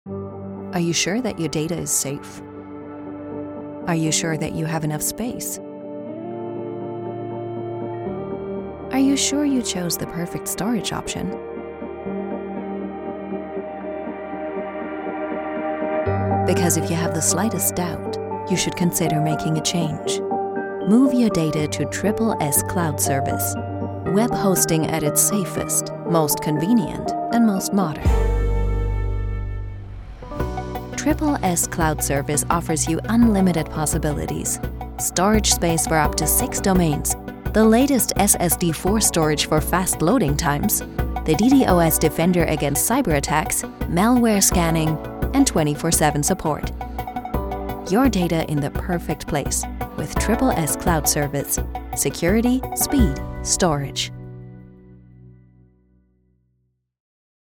dunkel, sonor, souverän, markant, sehr variabel, plakativ, hell, fein, zart
Commercial (Werbung)